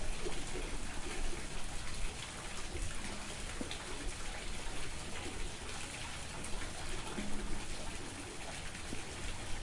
随机 " 下水道排水管涓涓细流 滴水成河2
Tag: 滴流 下水道 运行 漏极